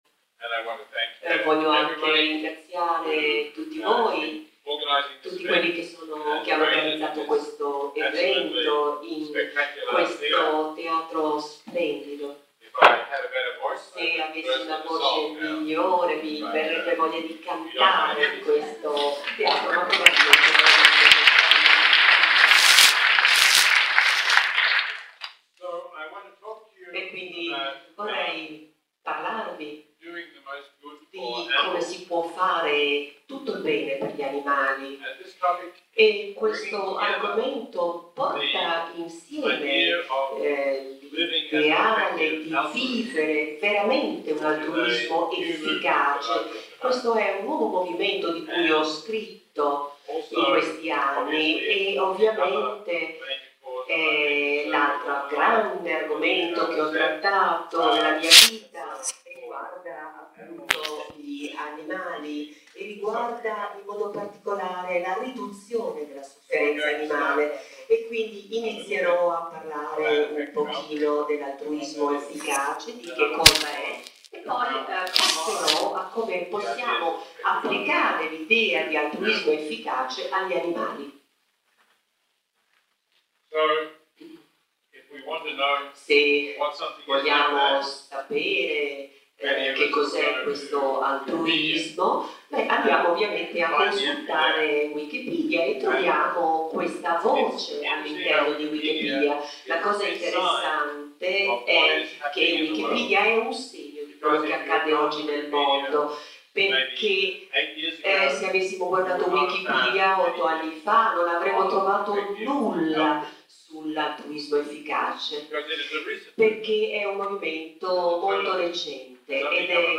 La consegna del Premio EMPTY CAGES 2016 si è svolta nella stupenda cornice del Teatro Carignano di Torino, gremito di pubblico, in un'anteprima della 12esima edizione di Torino Spiritualità (dal 28 settembre al 2 ottobre). Qui la registrazione audio della conferenza del Filosofo che è seguita alla premiazione: